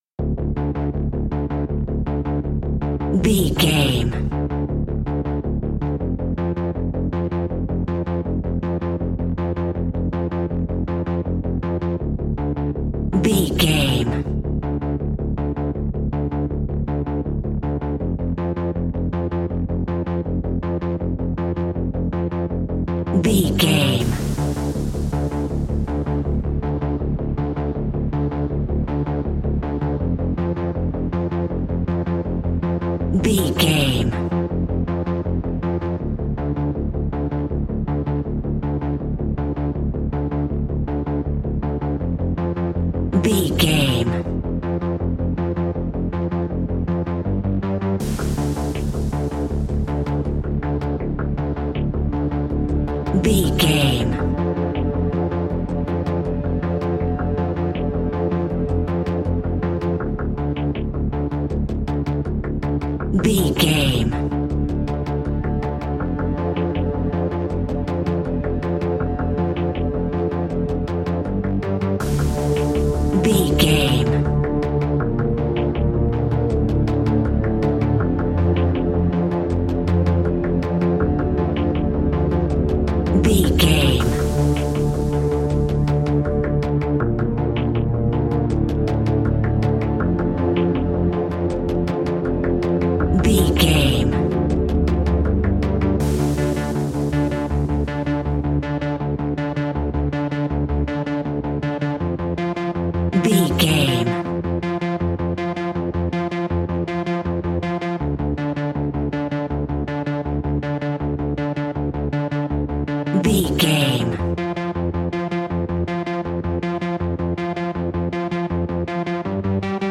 Aeolian/Minor
ominous
dark
eerie
synthesiser
strings
drums
percussion
horror music